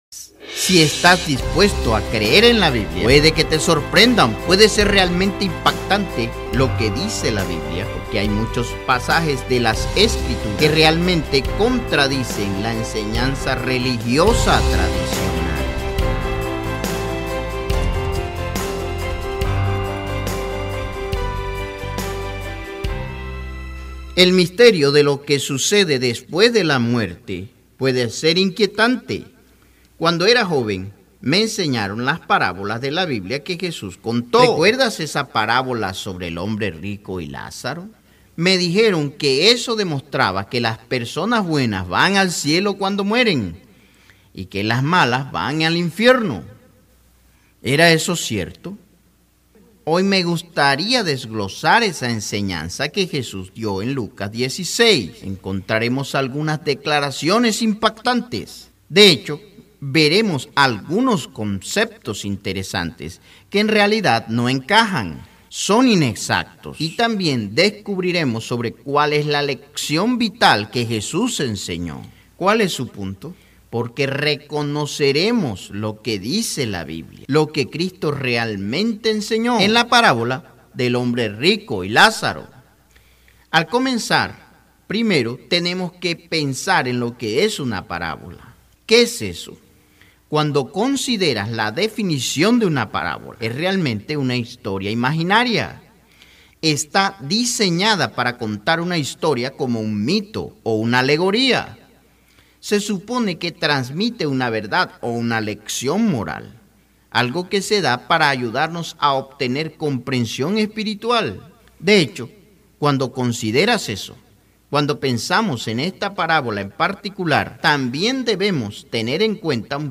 programa de televisión